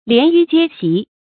連輿接席 注音： ㄌㄧㄢˊ ㄧㄩˊ ㄐㄧㄝ ㄒㄧˊ 讀音讀法： 意思解釋： 行并車，止同席。